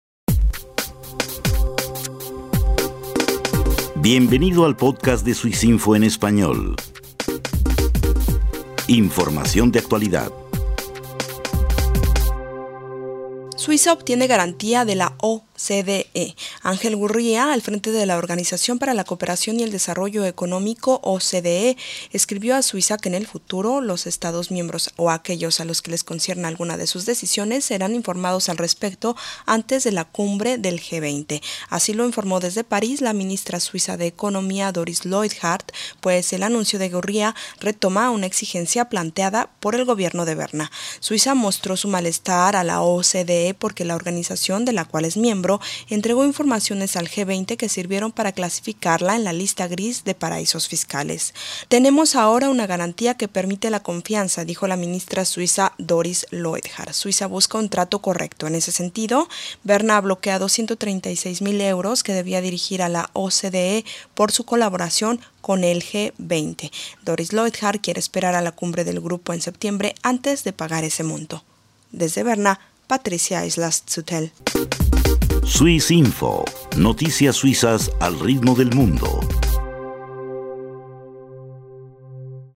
Informó la ministra Doris Leuthard desde París.